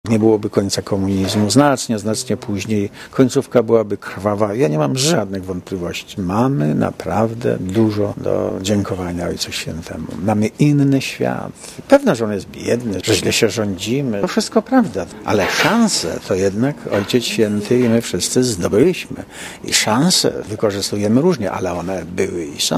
Mówi Lech Wałęsa